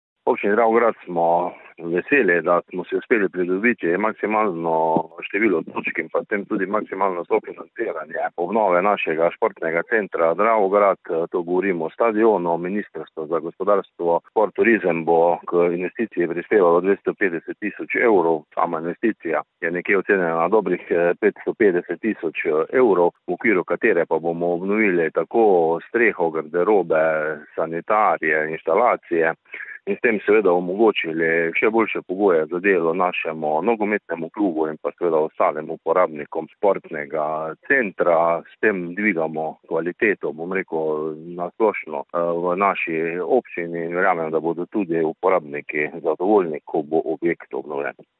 Obnova dravograjskega stadiona je ocenjena na dobre pol milijona evrov. Ministrstvo bo tako kot pri vseh izbranih naložbah prispevalo približno polovico, je razložil župan Občine Dravograd Anton Preksavec: